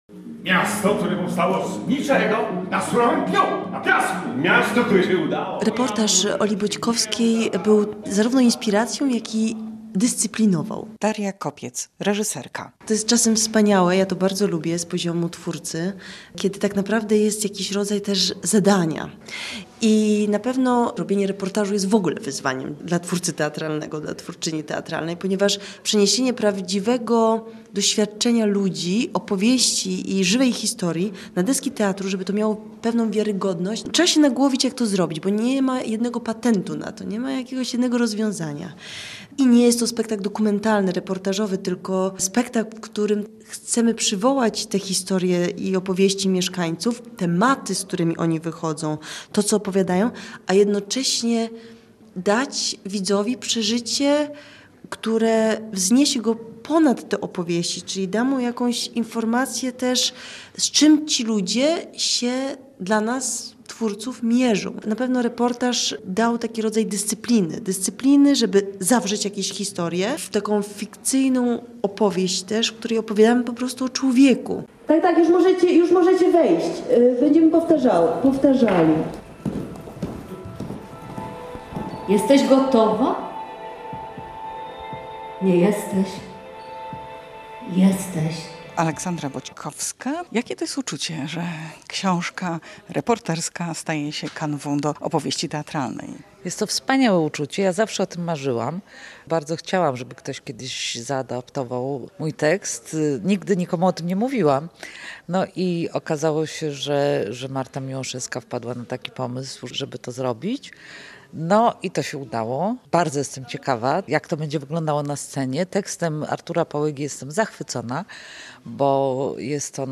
Posłuchaj materiału reporterki: